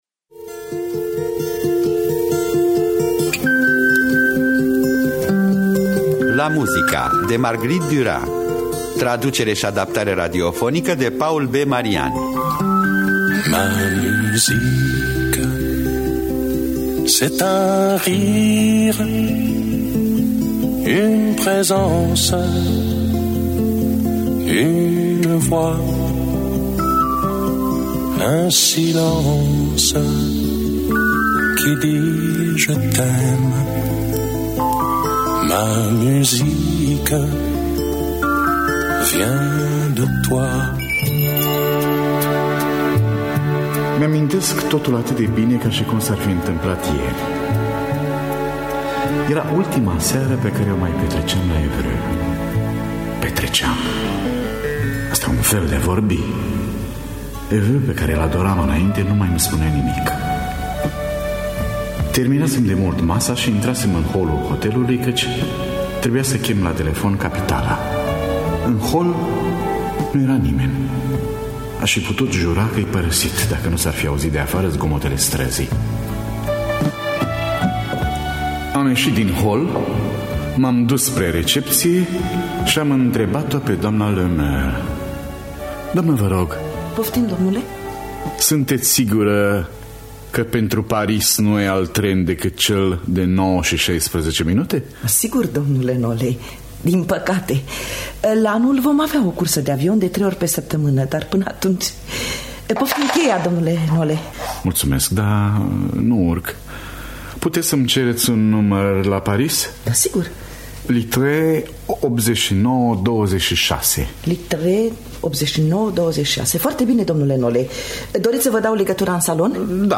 Traducerea şi adaptarea radiofonică de Paul B. Marian.